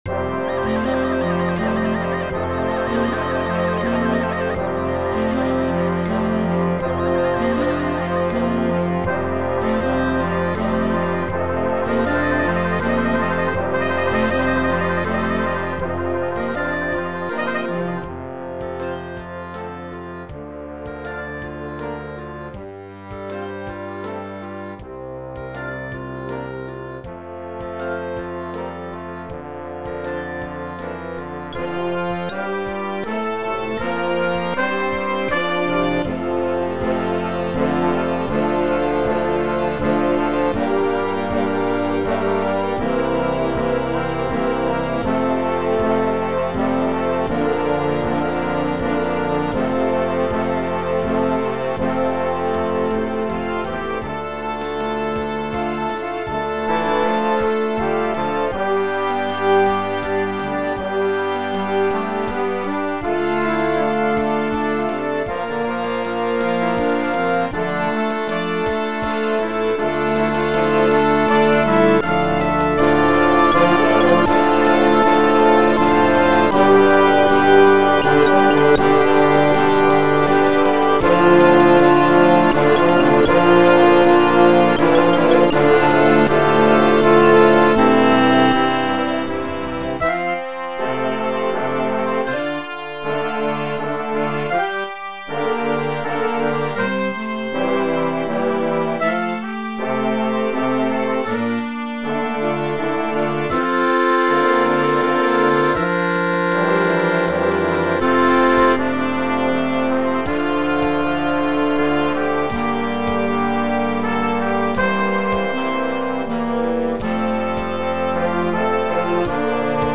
Brass Band oder Harmonie
(Elektronisch generietes Hörbeispiel)